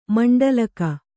♪ maṇḍalaka